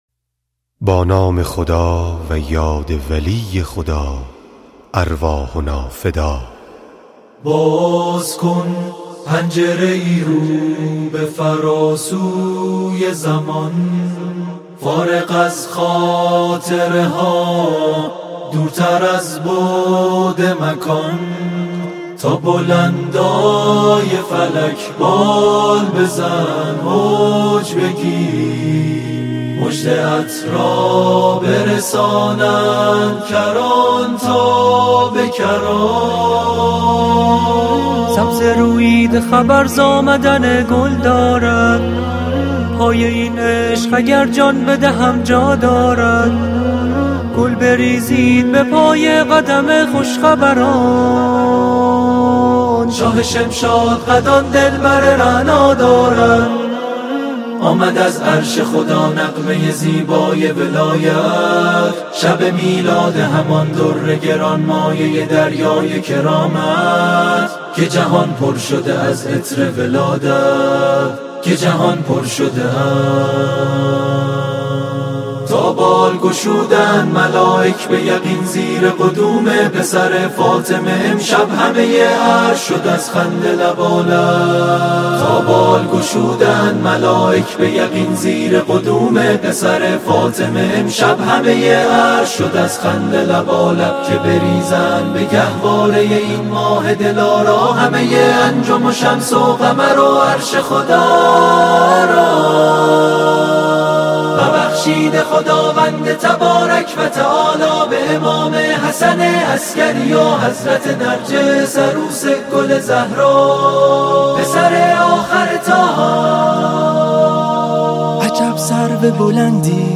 ویژه نیمه شعبان 1402